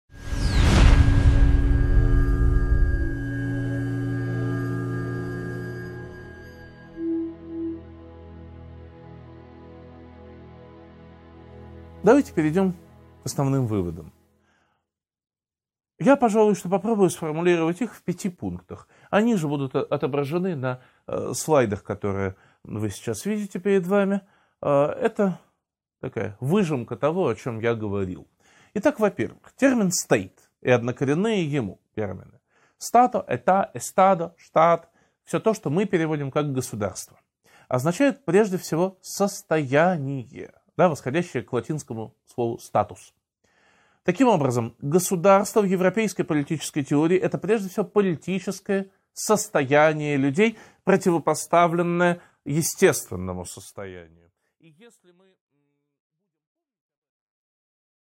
Аудиокнига 12.9 Выводы | Библиотека аудиокниг